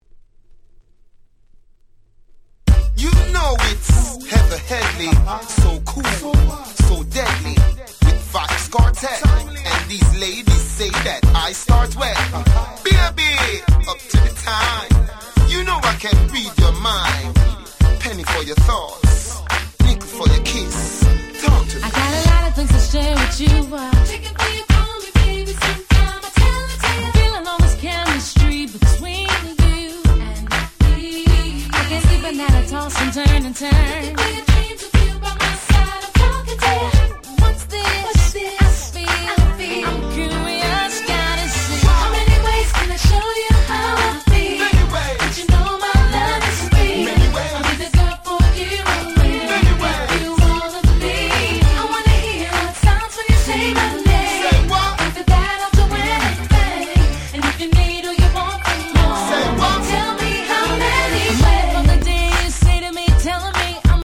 03' Very Nice UK R&B EP !!